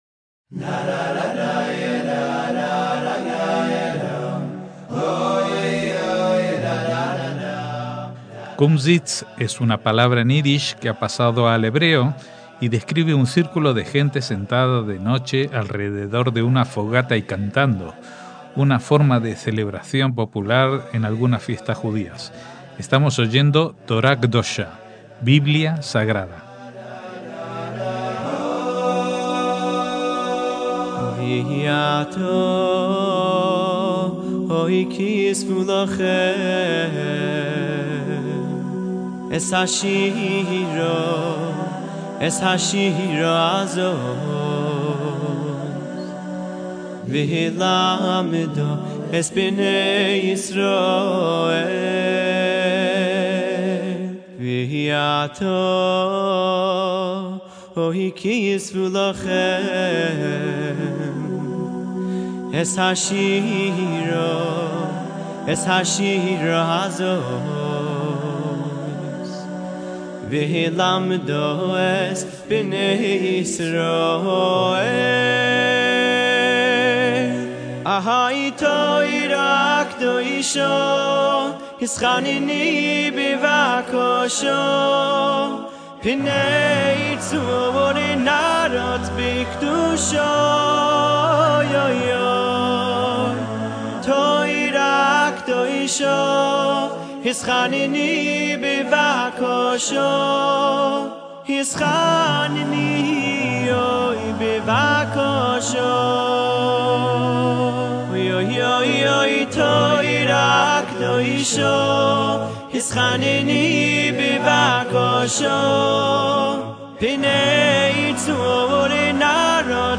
canto jasídico a capella